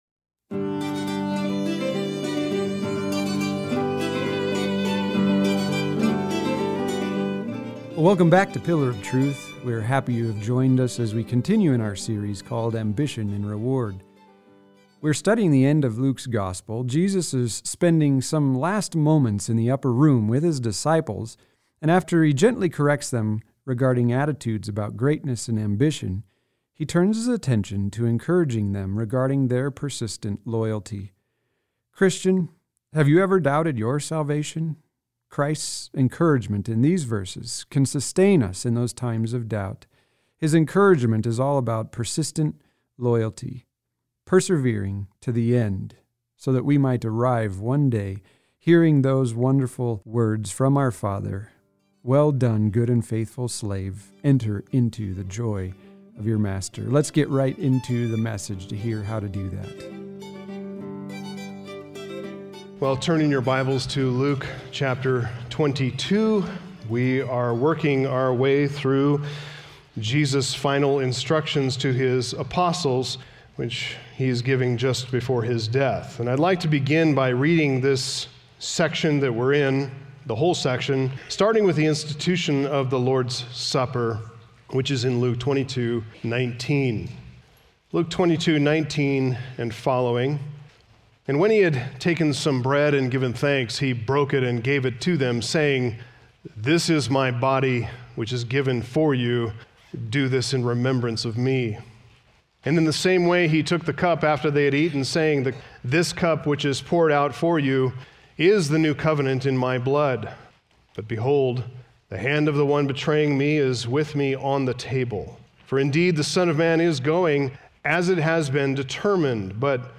Message Transcript